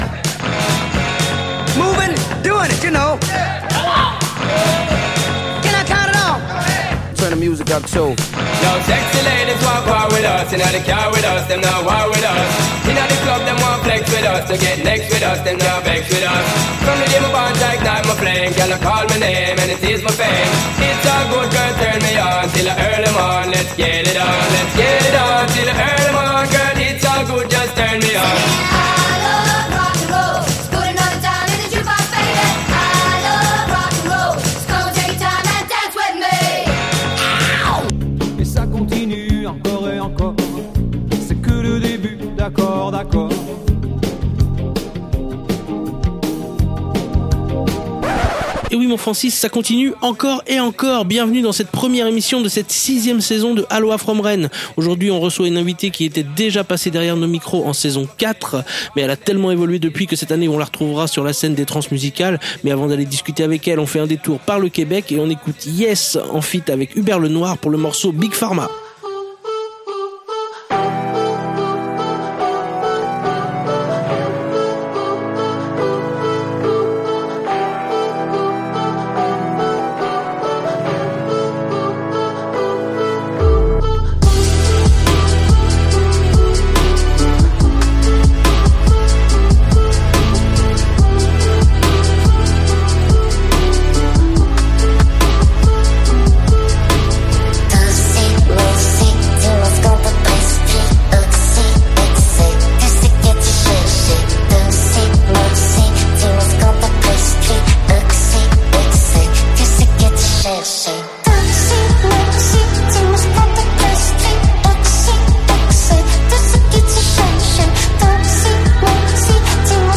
Globe trotter auditif , on discute avec un invité et on écoute des musiques du monde mais jamais de world-music.